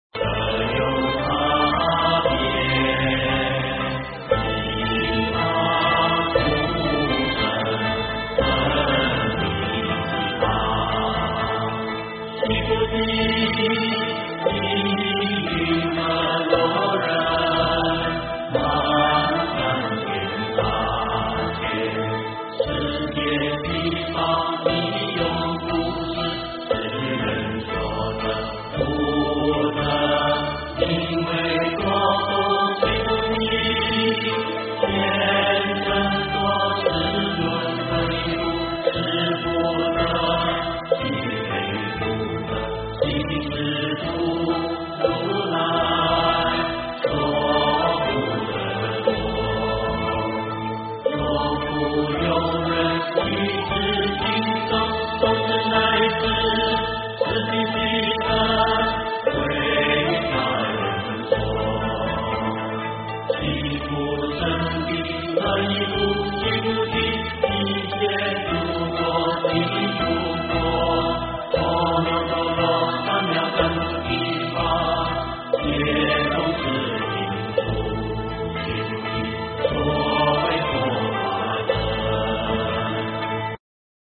金刚经-依法出生分第八 诵经 金刚经-依法出生分第八--未知 点我： 标签: 佛音 诵经 佛教音乐 返回列表 上一篇： 南无慈藏佛 下一篇： 金刚经-一相无相分第九 相关文章 心经--印良法师 心经--印良法师...